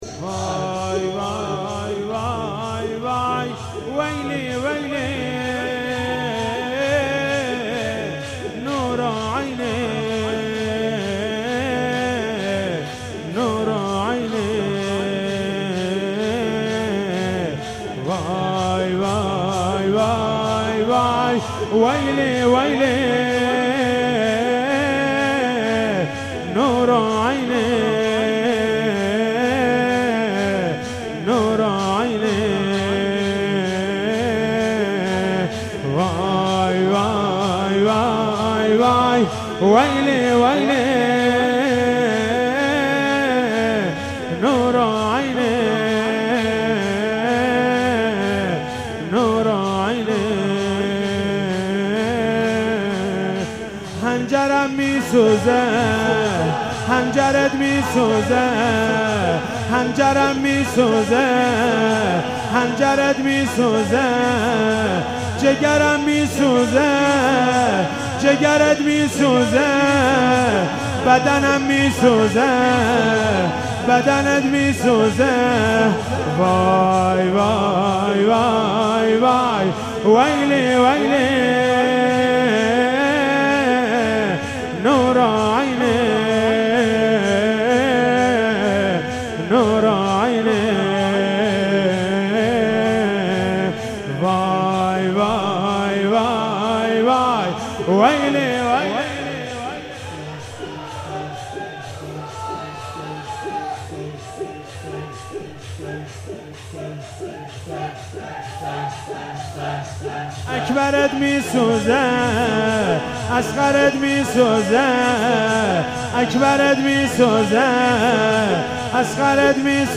شب دوم محرم96- شور